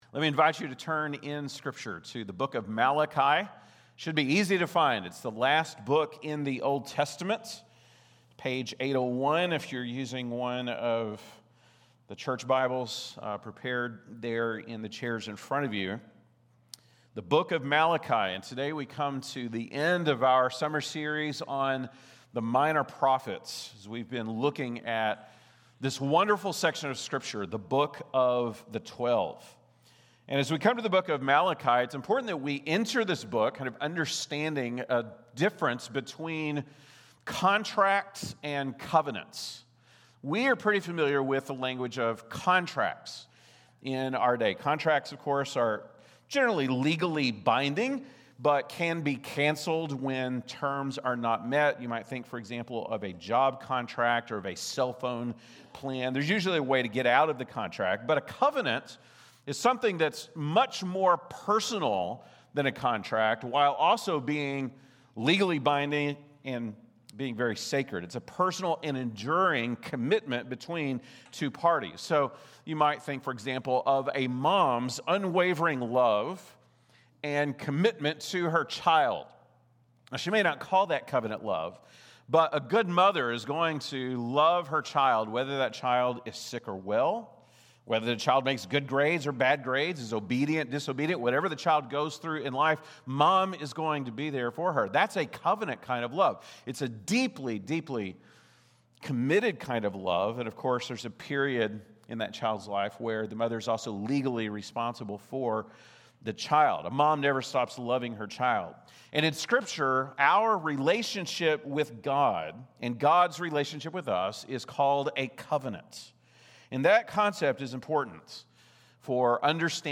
August 31, 2025 (Sunday Morning)